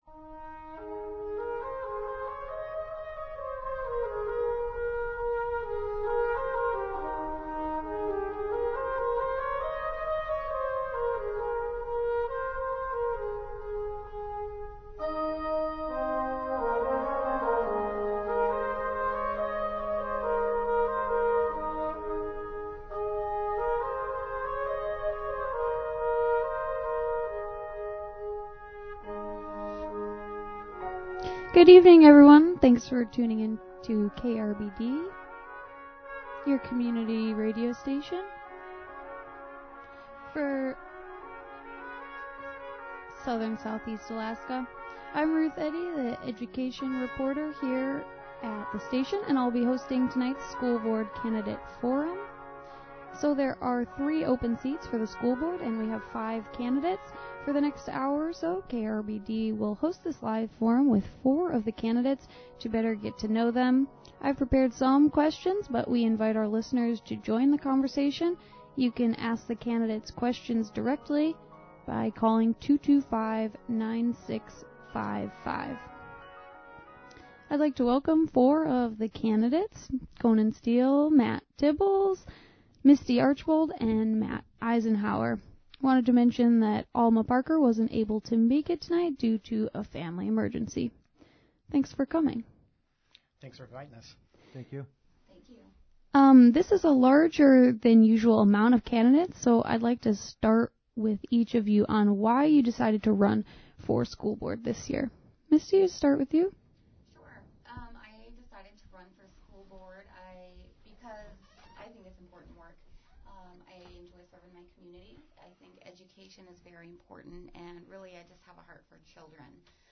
Here is the full audio from the luncheon: